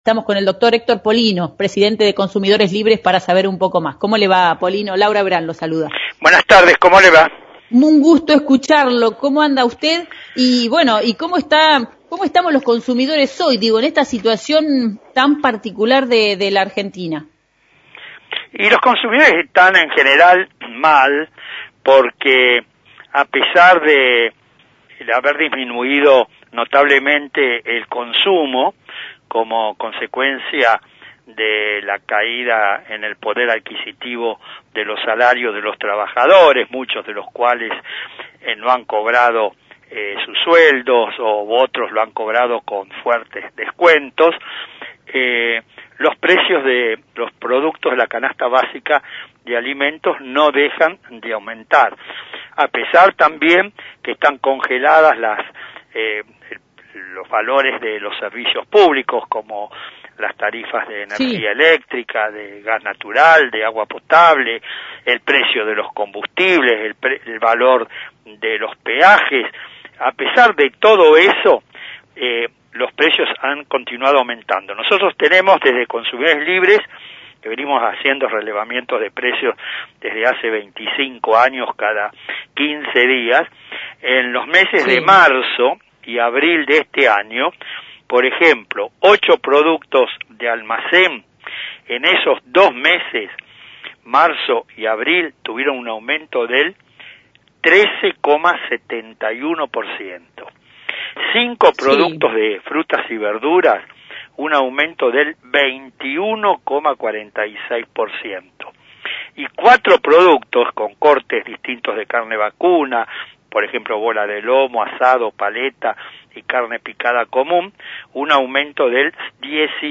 Entrevista a Dr. Héctor Polino – Presidente de Consumidores Libres